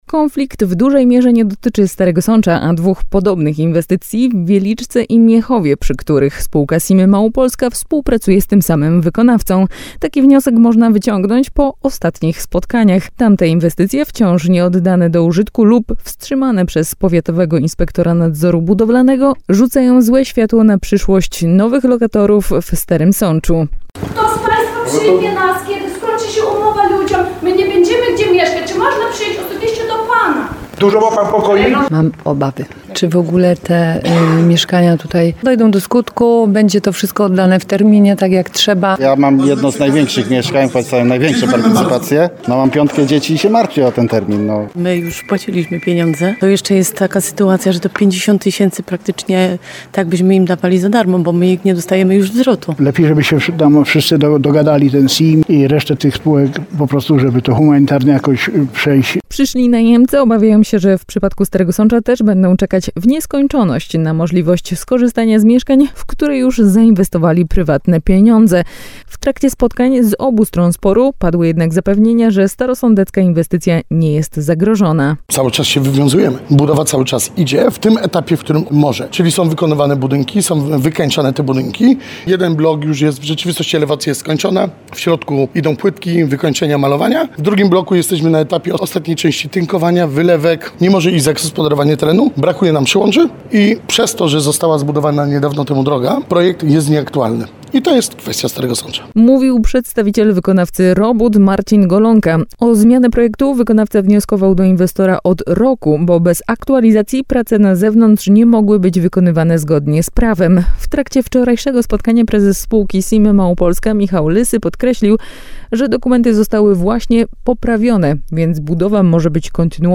W Starym Sączu odbyły się już dwa spotkania z udziałem potencjalnych najemców i obu stron sporu. Uczestniczyła w nich też nasza reporterka.